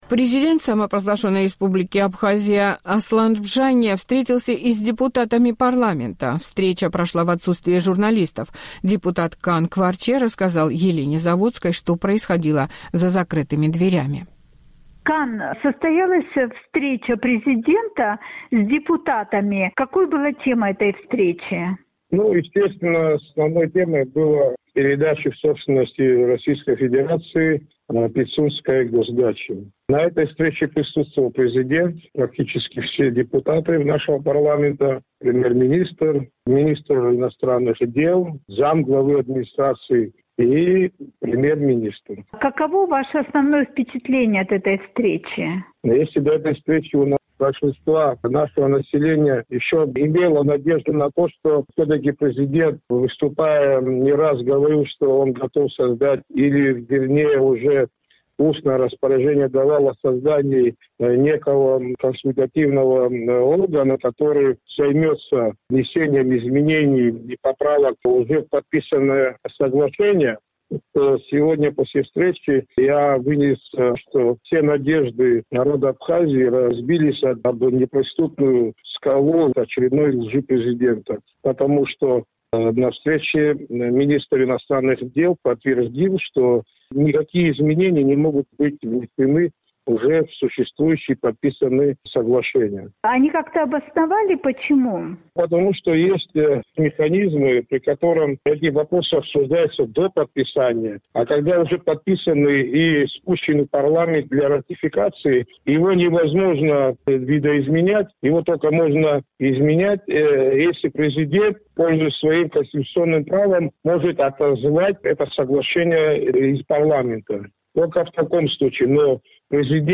Депутат Кан Кварчия рассказал «Эху Кавказа», что происходило за закрытыми дверями.